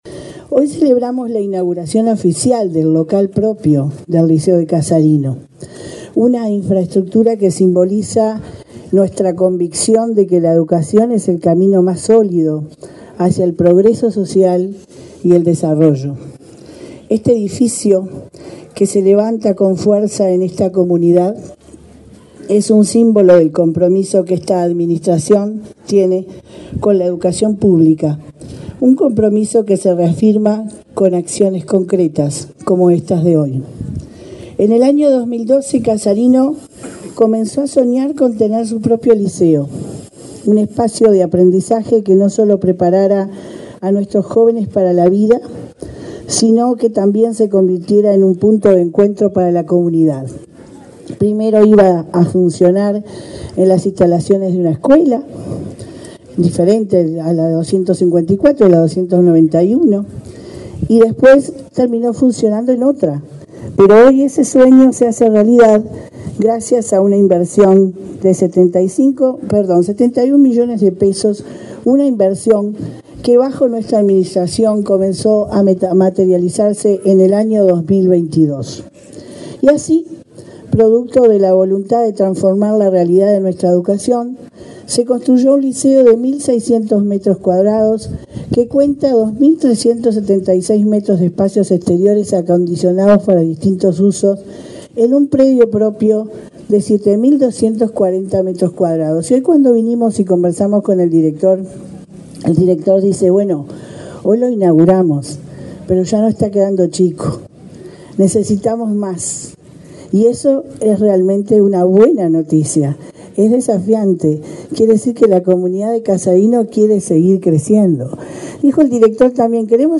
Palabras de autoridades de la ANEP en Canelones
Palabras de autoridades de la ANEP en Canelones 13/09/2024 Compartir Facebook X Copiar enlace WhatsApp LinkedIn Este viernes 13, la directora general de Secundaria, Jenifer Cherro, y la presidenta de la ANEP, Virginia Cáceres, participaron en la inauguración del edificio del liceo de Casarino, en el departamento de Canelones.